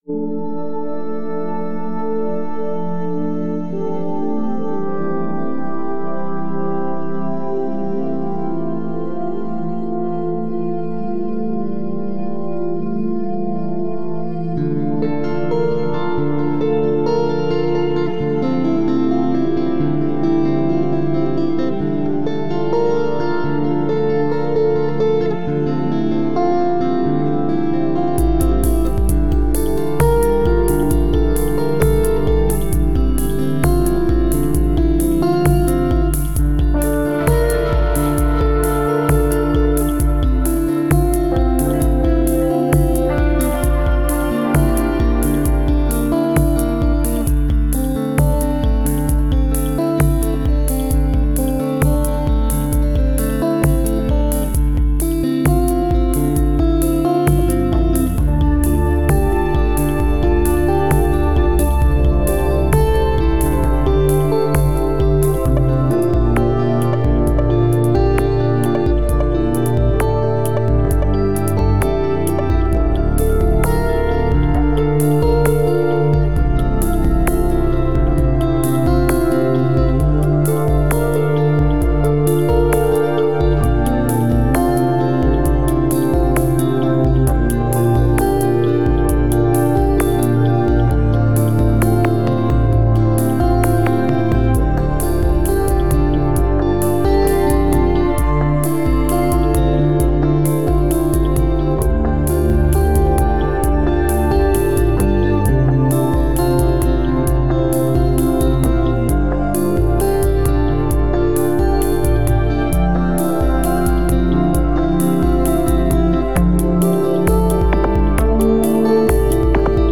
Live_Spacey_4-4_v03.mp3